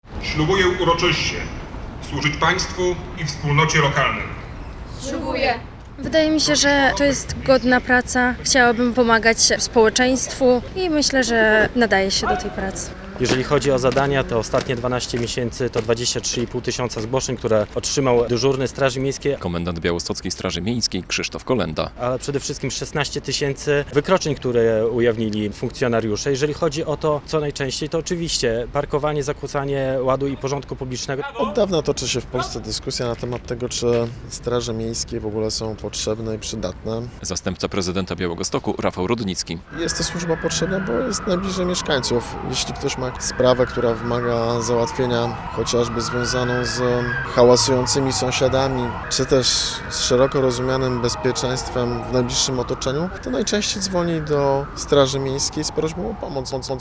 Straż Miejska w Białymstoku świętowała 32. rocznicę powstania - relacja
Uroczystości z okazji święta straży miejskiej odbyły się przy Pałacyku Gościnnym w Białymstoku.